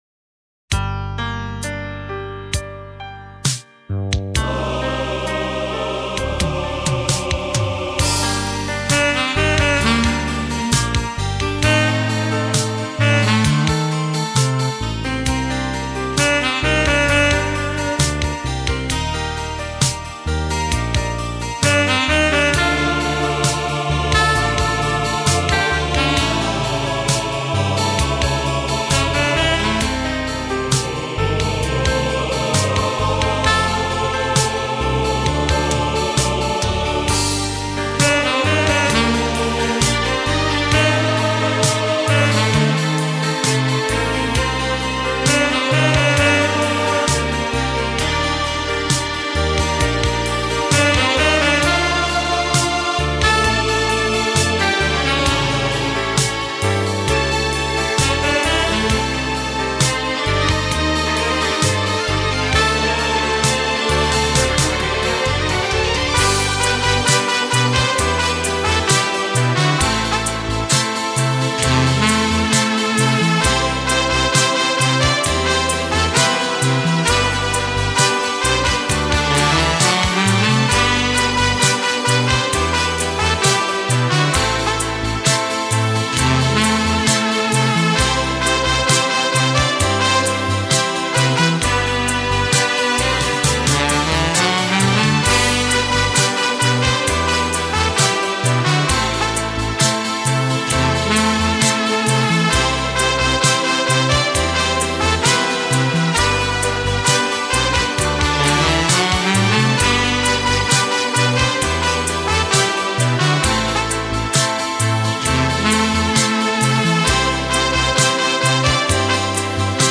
• Жанр: Блюз